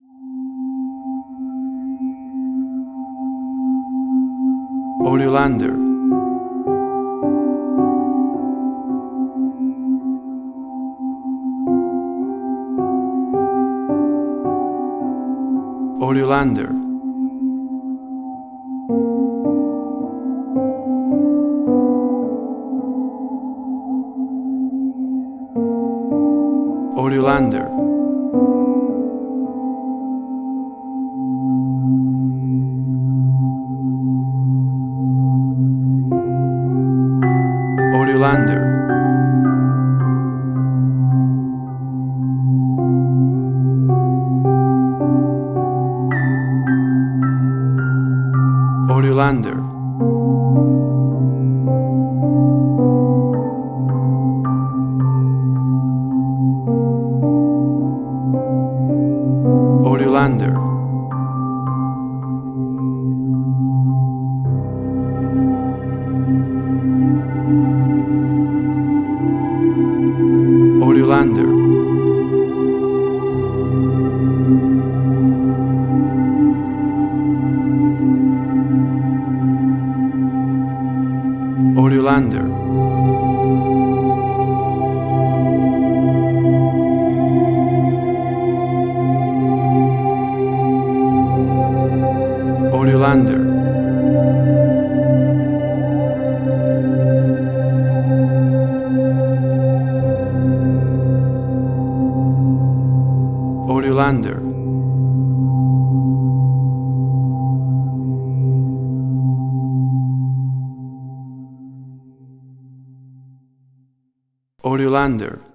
Suspense, Drama, Quirky, Emotional.